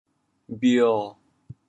潮阳拼音“bhio7”的详细信息